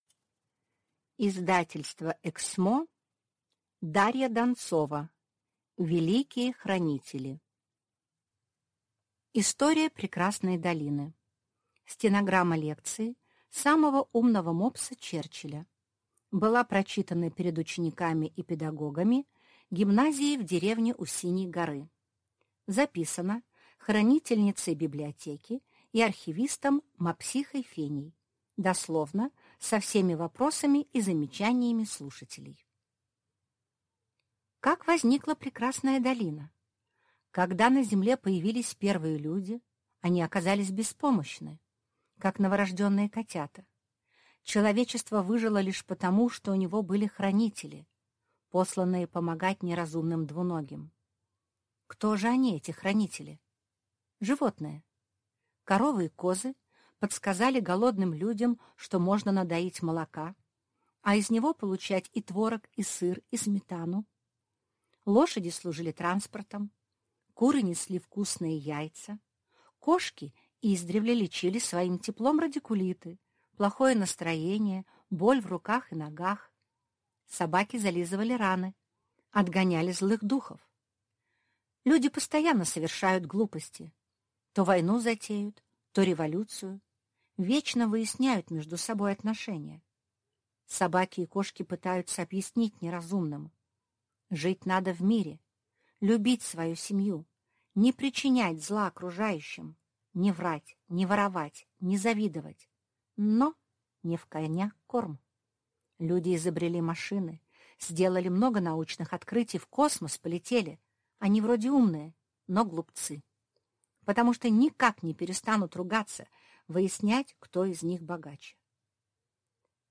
Аудиокнига Великие хранители | Библиотека аудиокниг